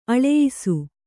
♪ aḷeyisu